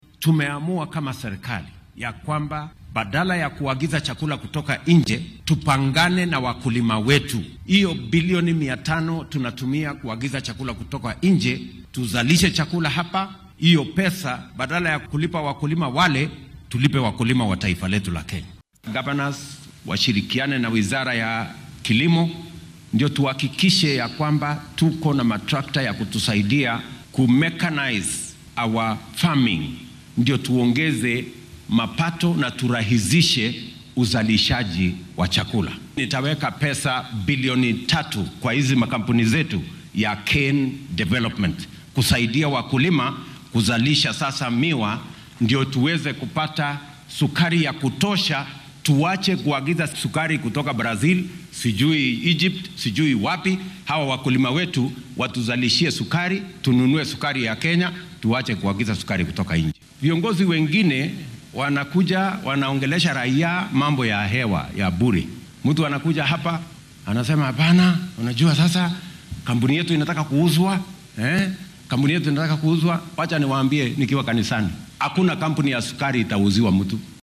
Madaxweynaha dalka William Ruto ayaa xilli uu ku sugnaa fagaaraha Approved School Grounds ee ismaamulka Kakamega sheegay in uunan jirin qorsho dowladeed oo lagu iibinaya warshadaha sokorta soo saaro.
Xilli uu ka hadlayay qorshaha dowladda ee ku aaddan wanaajinta wax soo saarka warshadaha sokorta ee dalka ayuu madaxweyne William Ruto yidhi.